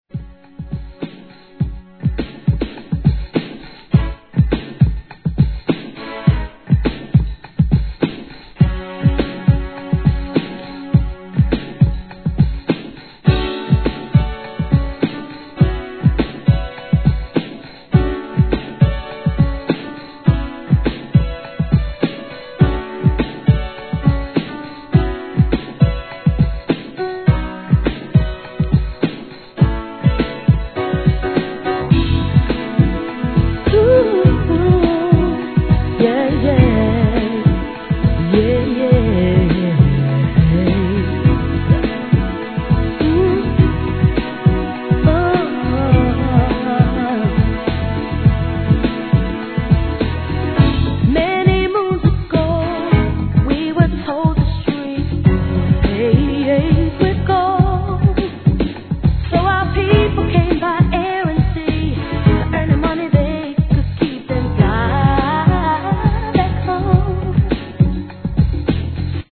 HIP HOP/R&B
絶品グランドビート